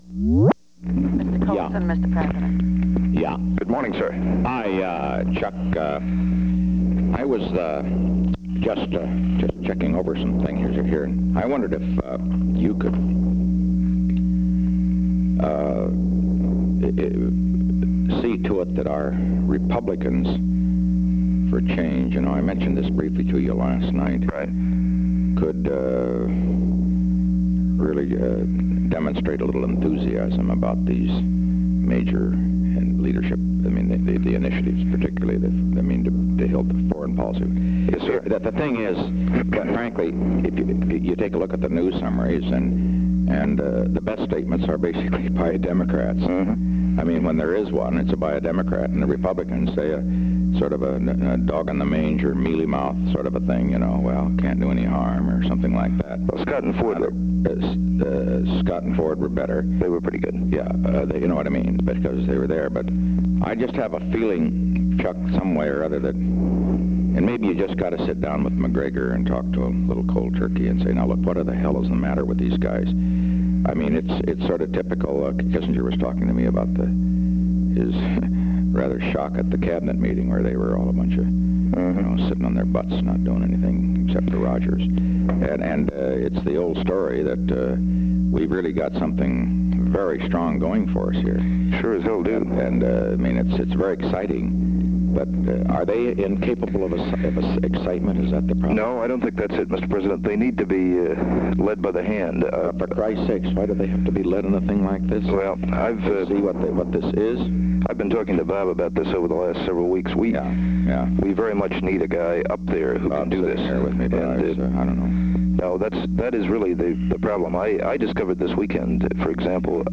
Location: White House Telephone
The President talked with Charles W. Colson.